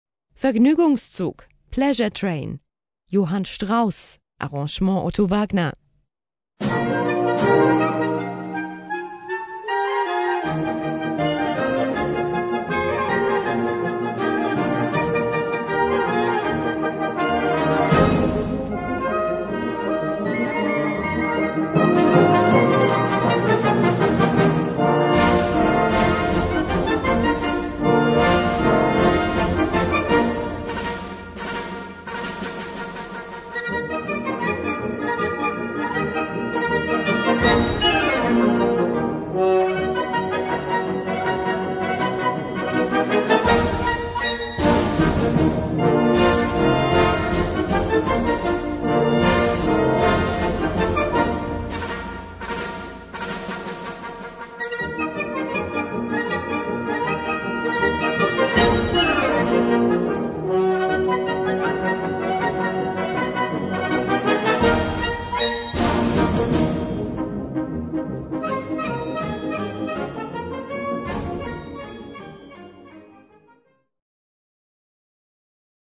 Gattung: Schnelle Polka
Besetzung: Blasorchester